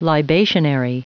Prononciation du mot libationary en anglais (fichier audio)
Prononciation du mot : libationary